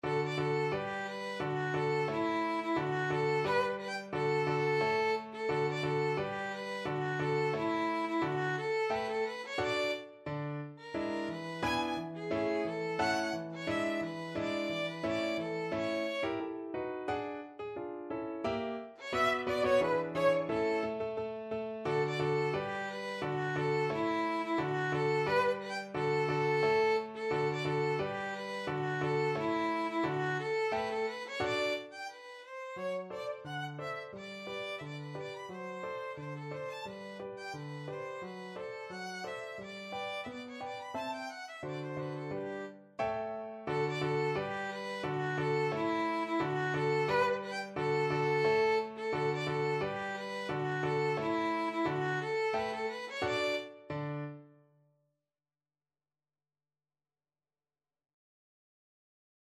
Classical Strauss II,Johann Fledermaus-Quadrille, Op.363 Violin version
Violin
D major (Sounding Pitch) (View more D major Music for Violin )
2/4 (View more 2/4 Music)
~ = 88 Stately =c.88
Classical (View more Classical Violin Music)